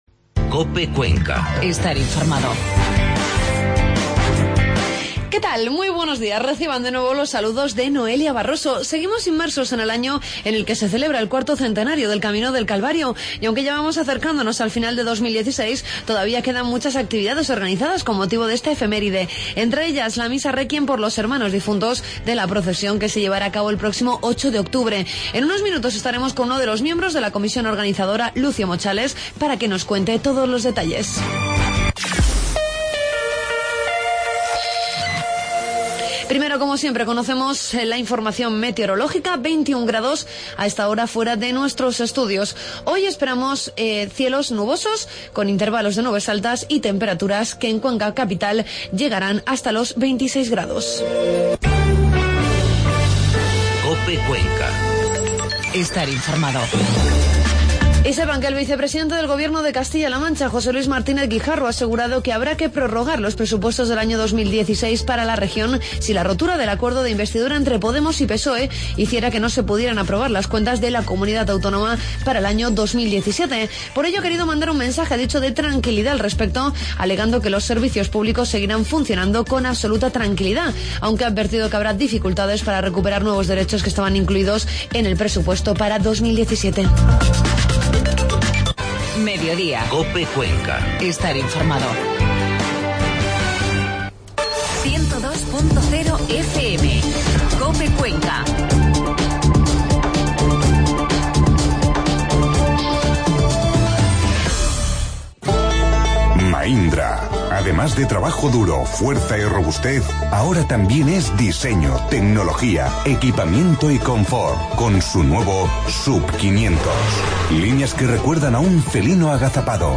Hablamos sobre la misa de réquiem por los hermanos difuntos de la Procesión Camino del Calvario que se celebrará con motivo del IV Centenario el próximo 8 de octubre. Entrevistamos a unos de los miembros de la comisión organizadora